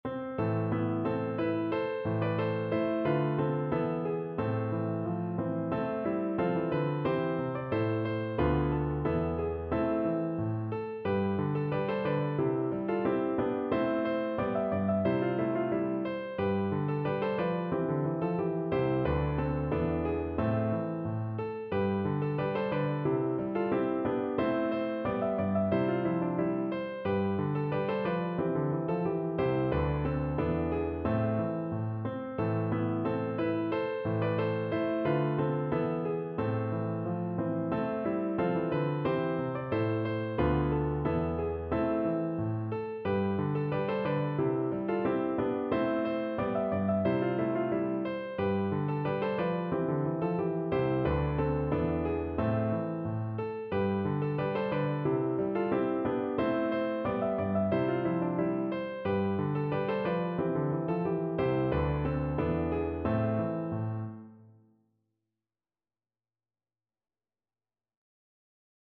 Piano version
No parts available for this pieces as it is for solo piano.
= 90 A
2/2 (View more 2/2 Music)